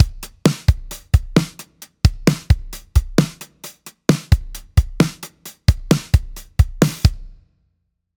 キックの1/8ずらし
オフビートへと打点をずらすことが、結果として「前のめり」や「タメ」のような表現となって、リズムに少しユニークさが出てきました。
r1-synco-drum-kick8th.mp3